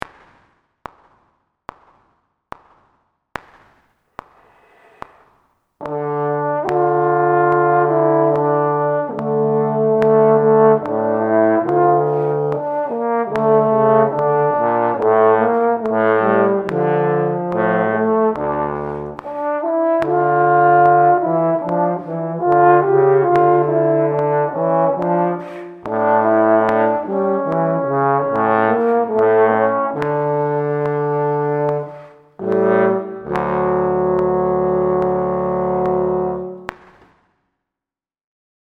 Here’s the audio of the (individual) harmony parts.
D minor
Min-02-d.mp3